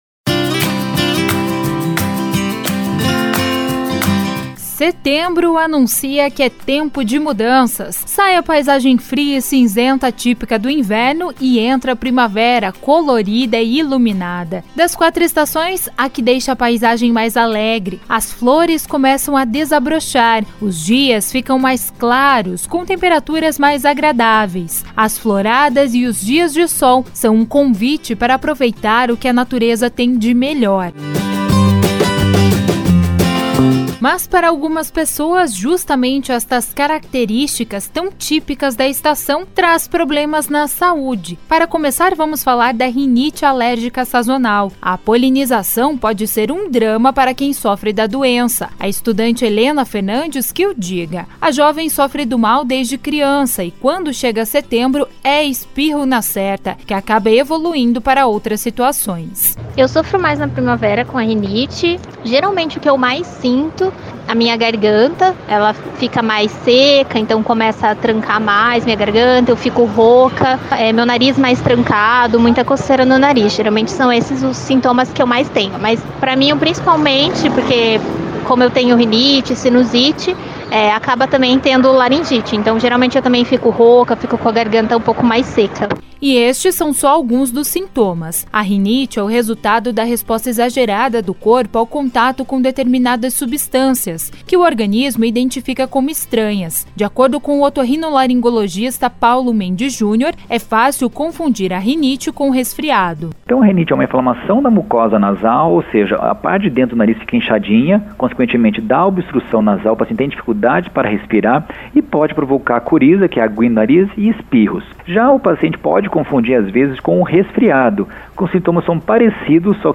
Reportagem 01- Rinite